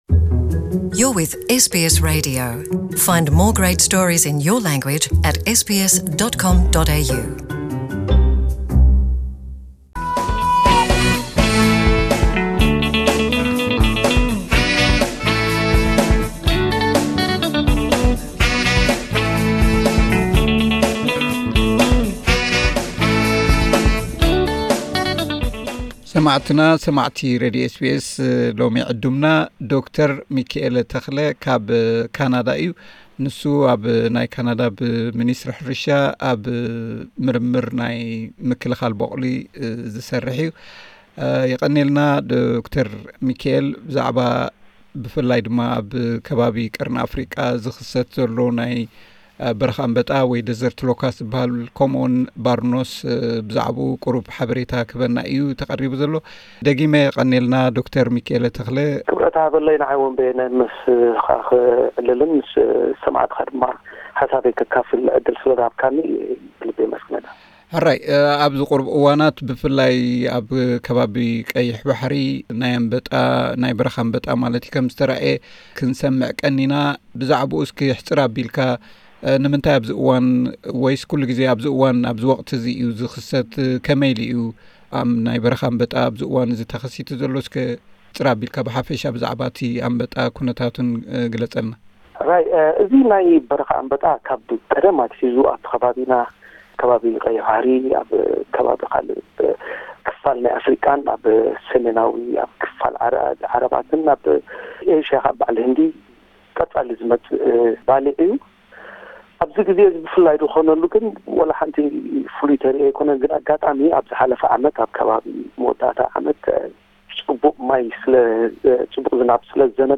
Locust invasion emergency in the Red Sea - Interview